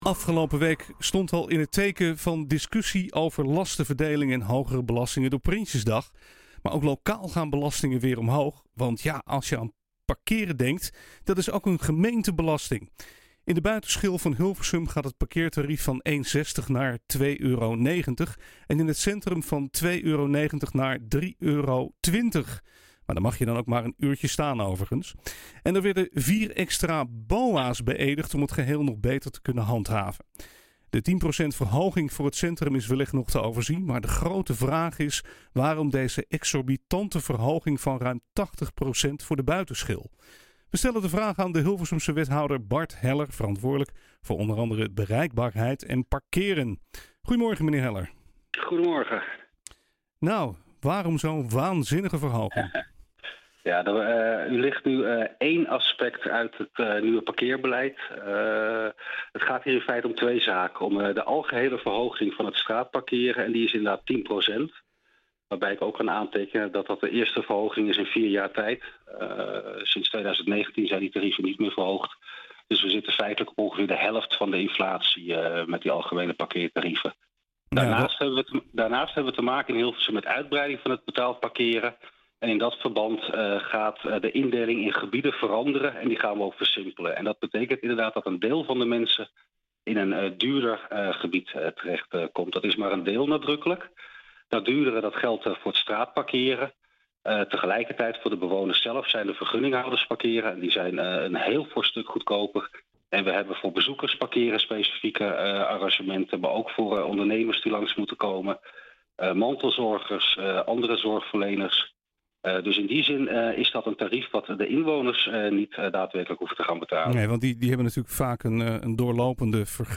We stellen de vraag aan de Hilversumse wethouder Bart Heller verantwoordelijk voor o.a. bereikbaarheid en parkeren.